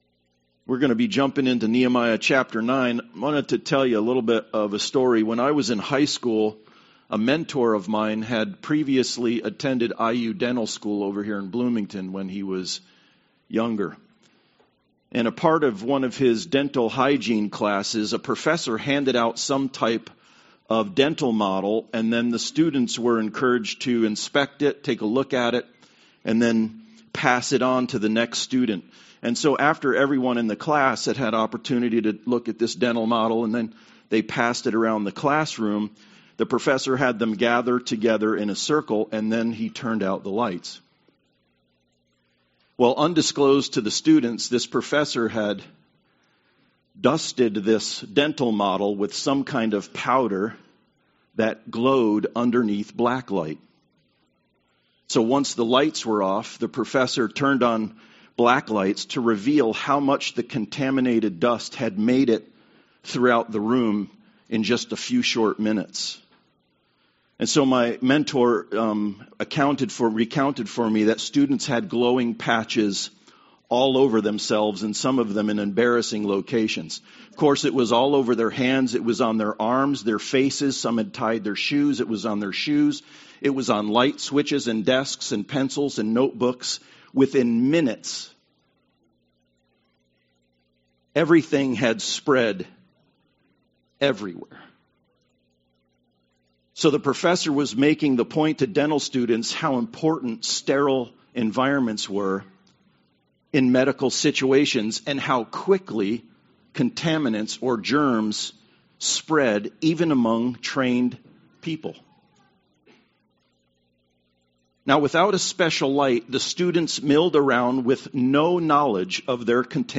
Nehemiah 9 Service Type: Sunday Service Worship of the living God